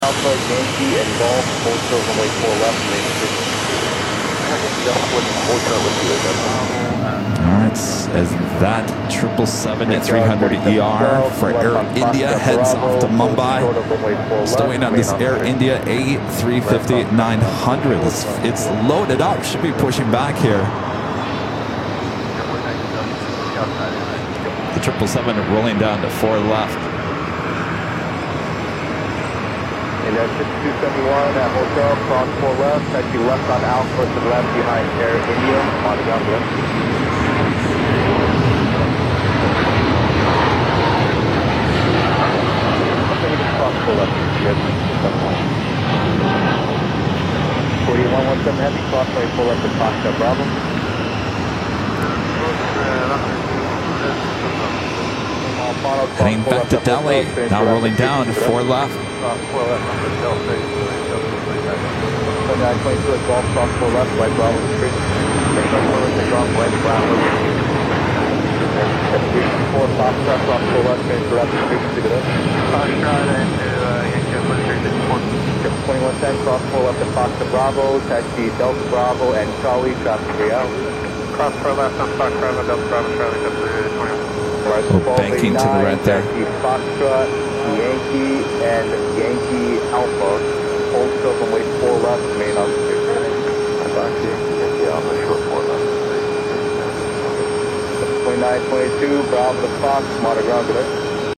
Air India 🇮🇳 double feature at JFK. Both the 777-300ER and the sleek A350-900 departed around the same time during Airline Videos’ live broadcast from the TWA hotel rooftop in New York.